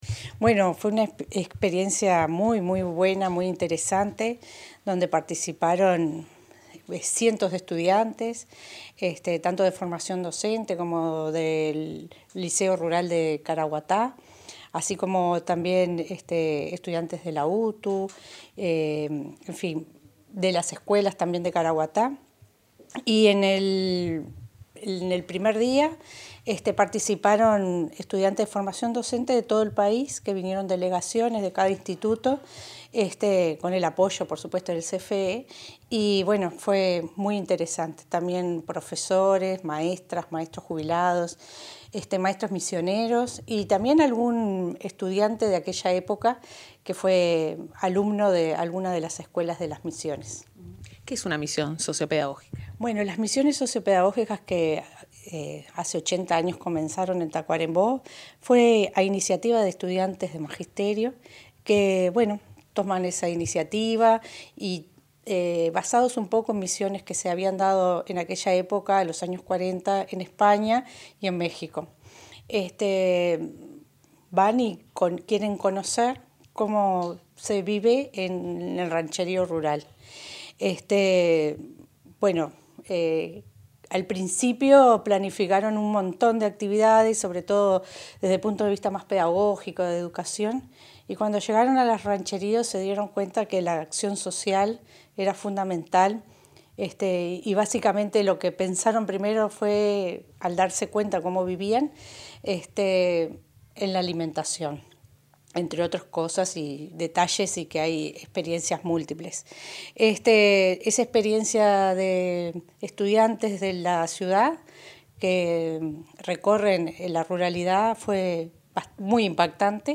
Declaraciones de la subsecretaria de Educación, Gabriela Verde